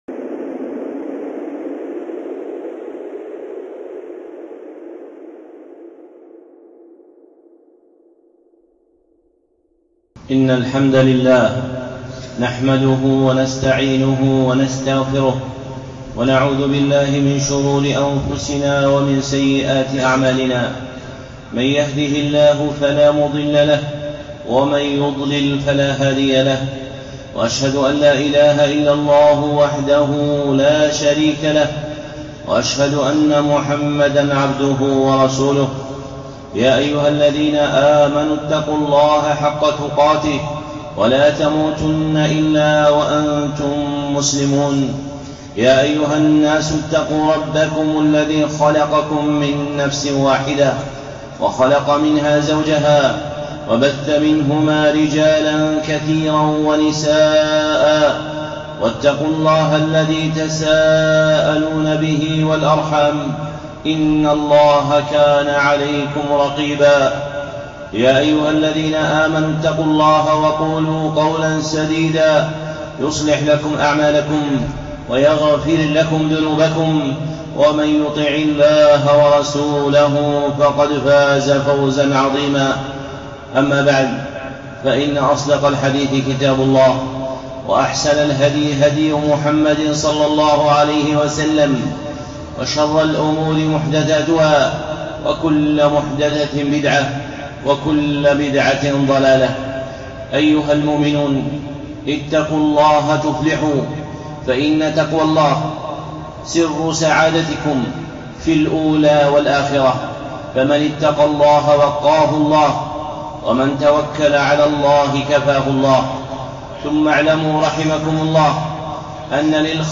الخطب المنبرية